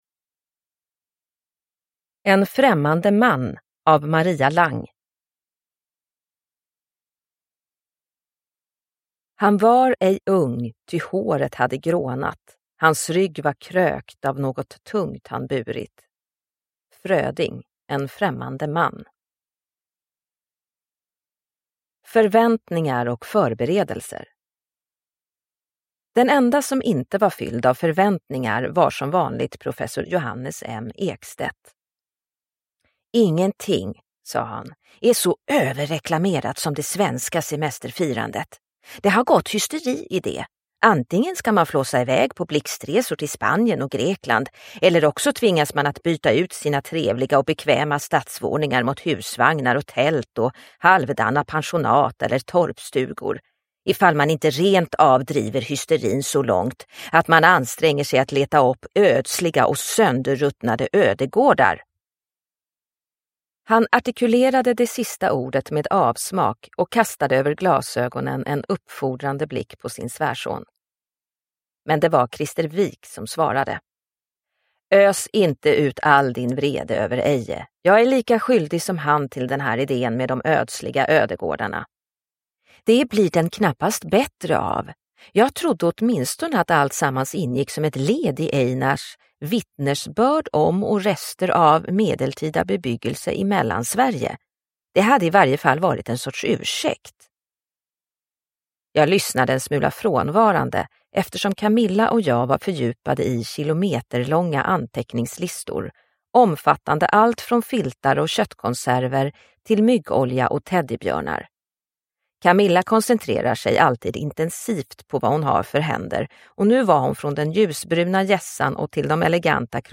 En främmande man – Ljudbok